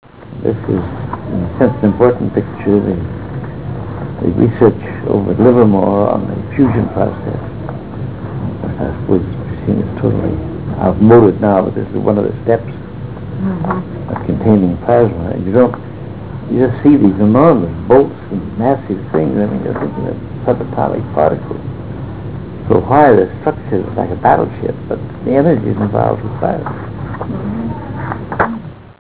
233Kb Ulaw Soundfile Hear Ansel Adams discuss this photo: [233Kb Ulaw Soundfile]